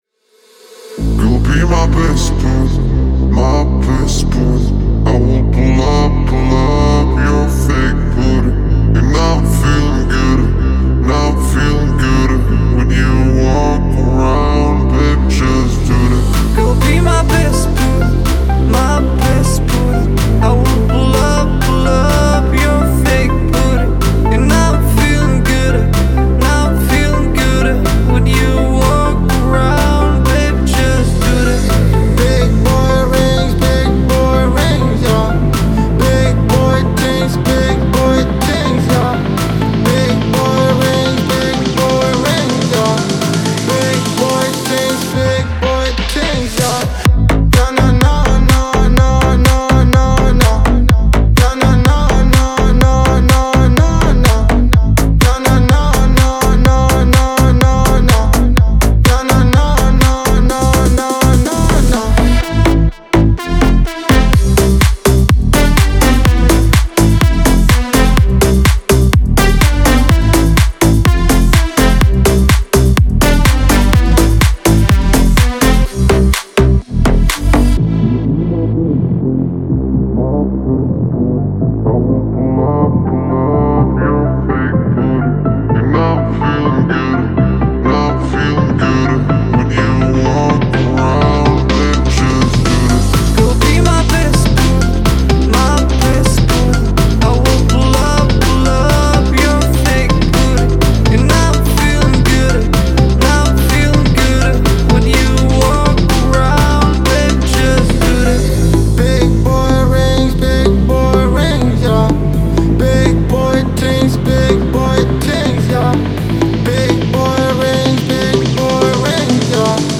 это энергичная и uplifting композиция в жанре поп